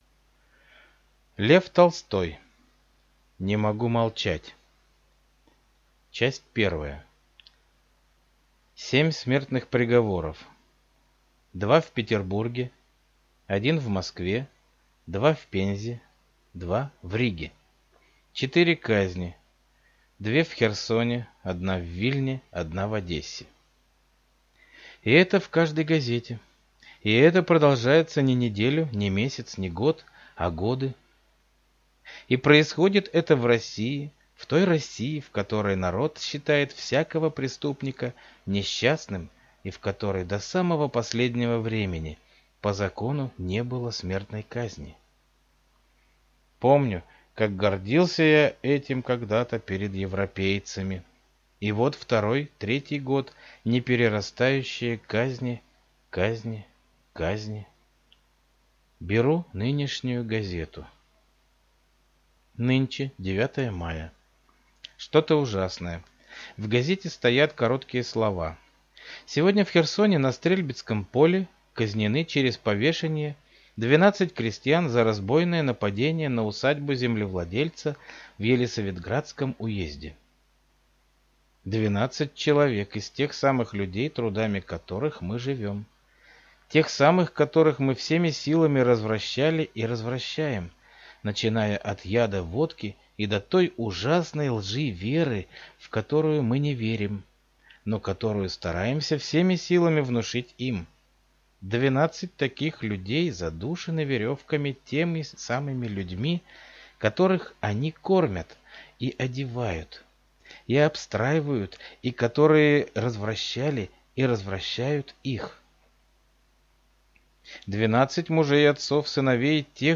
Аудиокнига Не могу молчать | Библиотека аудиокниг